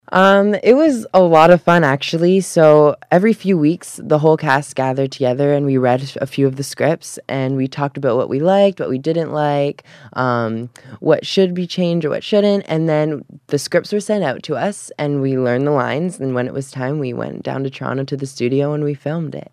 In a few highlights from the interview….